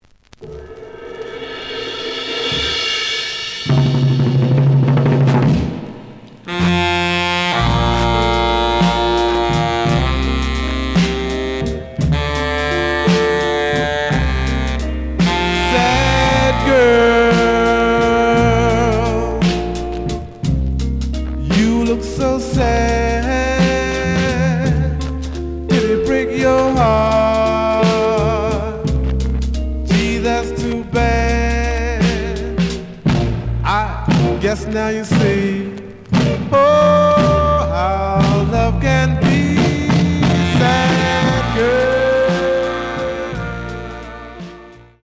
The audio sample is recorded from the actual item.
●Format: 7 inch
●Genre: Soul, 60's Soul
Some noise on parts of A side.